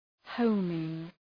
Προφορά
{‘həʋmıŋ}
homing.mp3